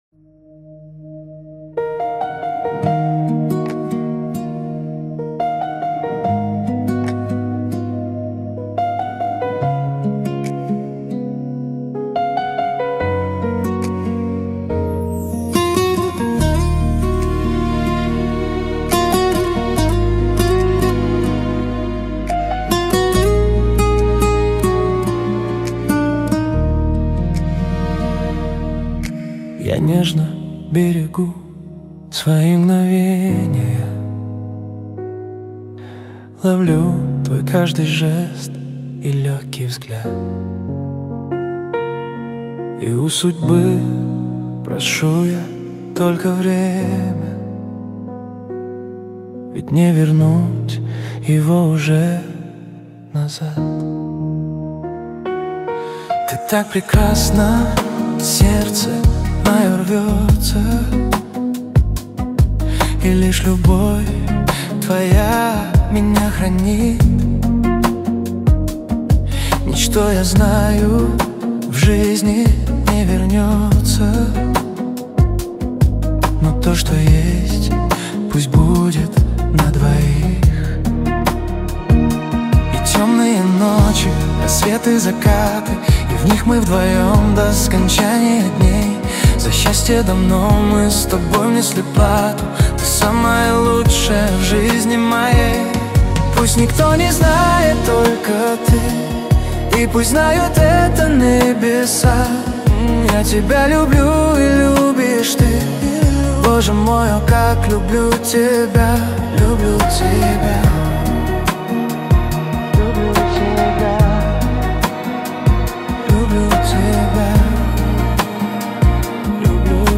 13 декабрь 2025 Русская AI музыка 74 прослушиваний